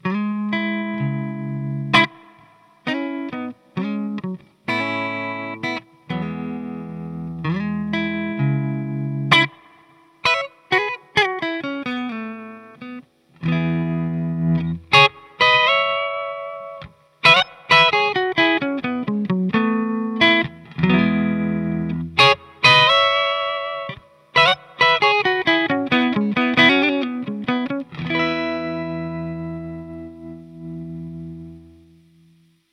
Brillante Höhen und ein voller Clean-Sound sind für das Topteil kein Problem.
Der Blackface 22 Reverb liefert einen sehr schönen, leicht angezerrten Sound mit vielen harmonischen Obertönen.
TAD Blackface 22 Reverb Test Klangbeispiele
Der Verstärker überzeugt in den Klangtests durch seine große Flexibilität, die Sounds von cleanem Pop bis in den verzerrten Rock Bereich ermöglicht.